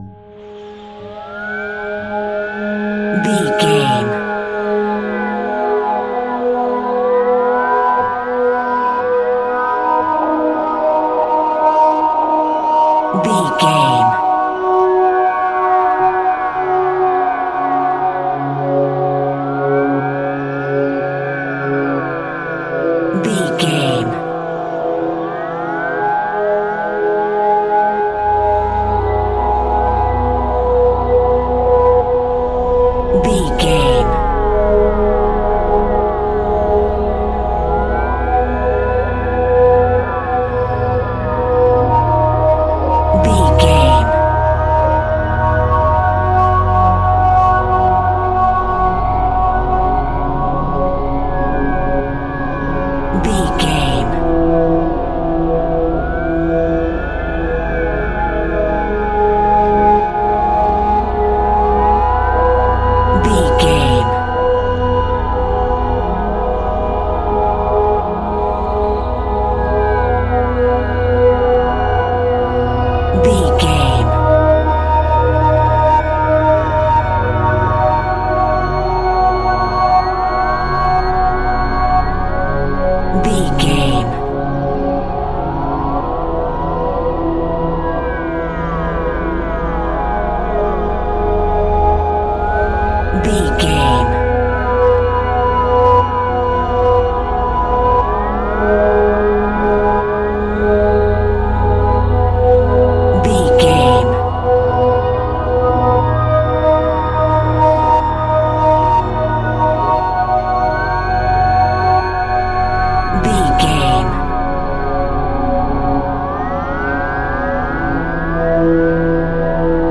Thriller
Atonal
tension
ominous
haunting
eerie
synthesizer
horror
cymbals
gongs
viola
french horn trumpet
taiko drums
timpani